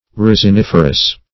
Search Result for " resiniferous" : The Collaborative International Dictionary of English v.0.48: Resiniferous \Res`in*if"er*ous\ (r?z`?n-?f"?r-?s), a. [Resin + -ferous: cf. F. r['e]sinif[`e]re.]
resiniferous.mp3